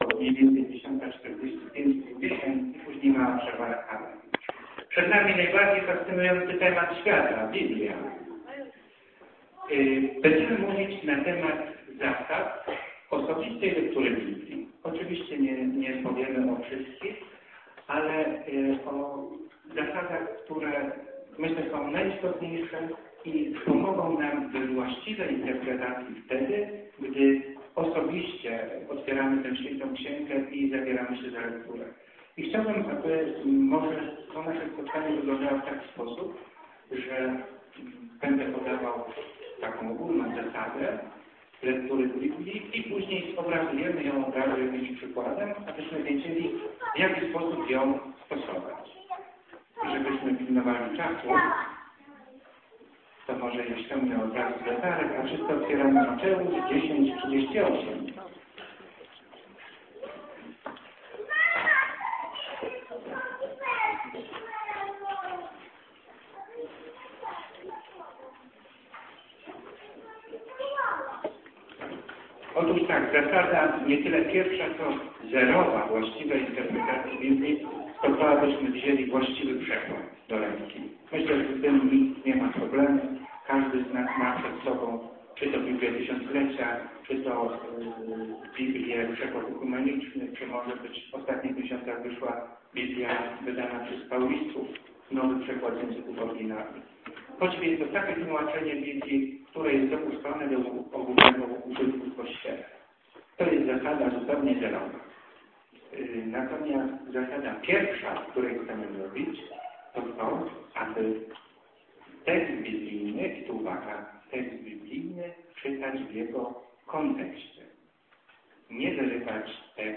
Majowe rekolekcje Wsp�lnoty
Wyk�ad